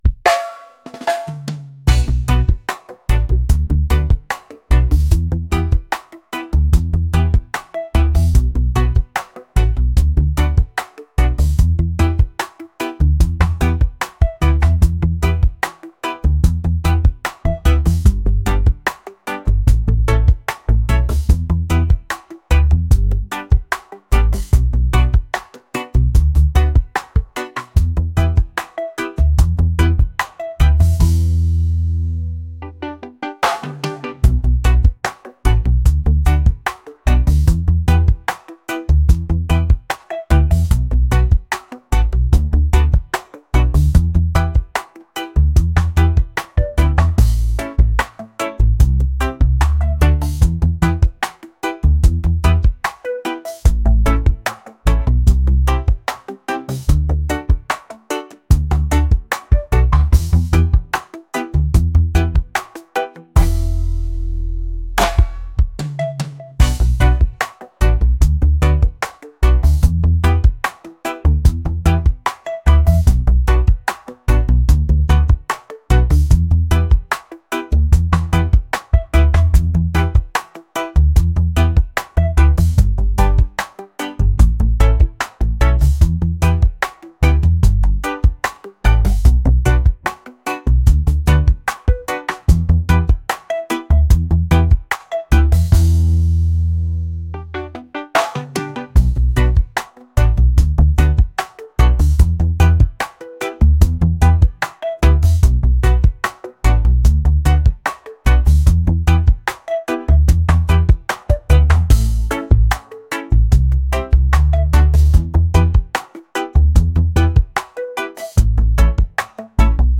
reggae | lofi & chill beats